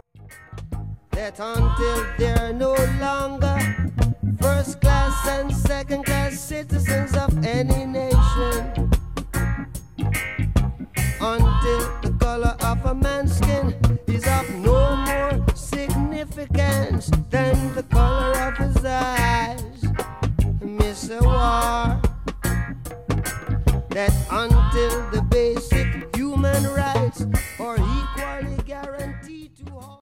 신시사이저는 이 앨범에서 두드러지게 사용되어, 록 기타의 강한 요소와 함께 곡에 화려한 색채를 더했다.